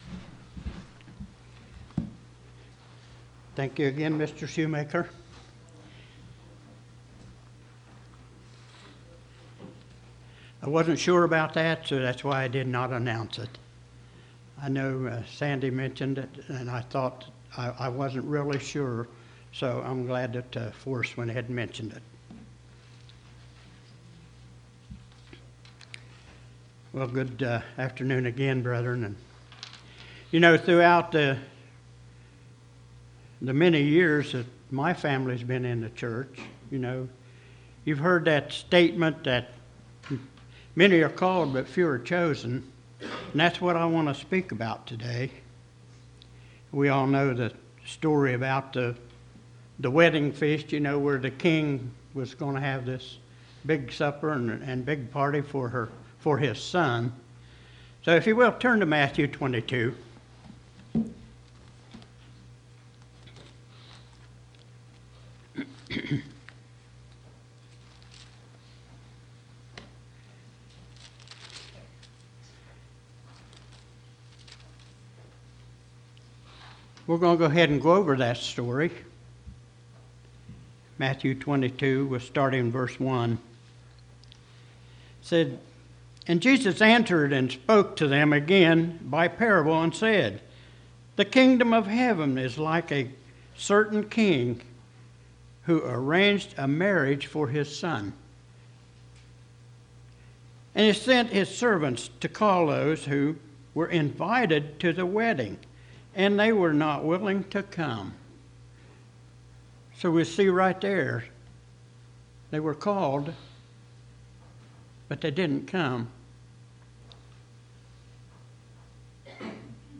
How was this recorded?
Given in Portsmouth, OH